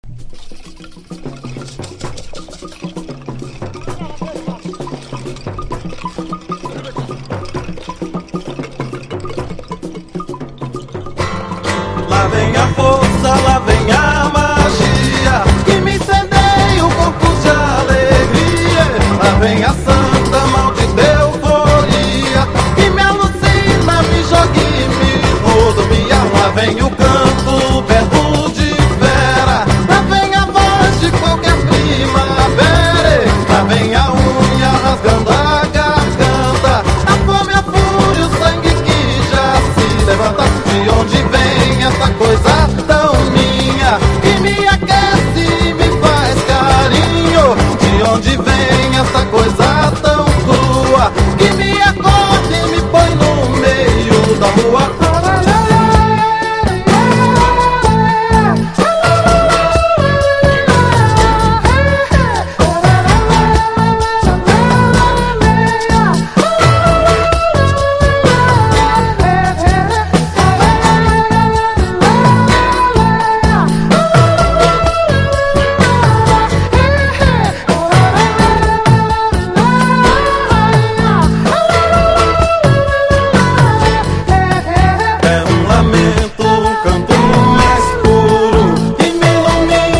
FREE SOUL
ブレイク# FREE / SPIRITUAL